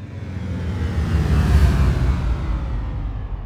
shipDescend.wav